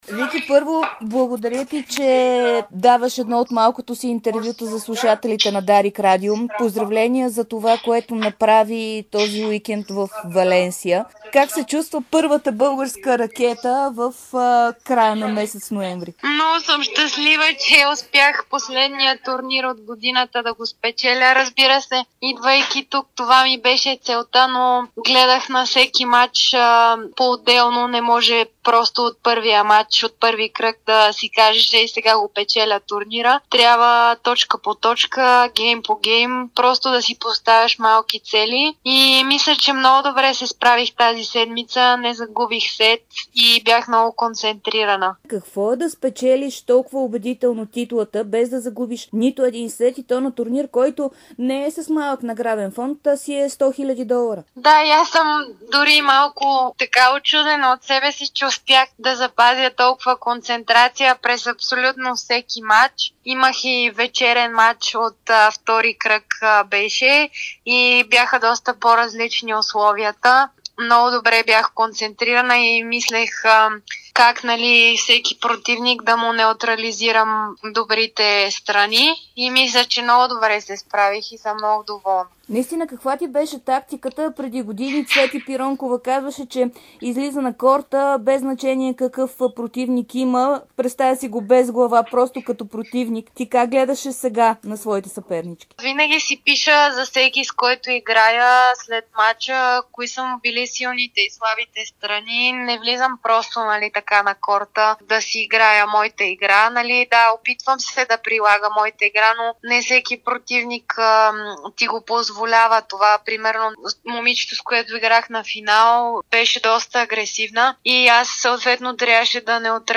Първата ни ракета при жените Виктория Томова даде своето първо за годината ексклузивно интервю пред Дарик радио и dsport.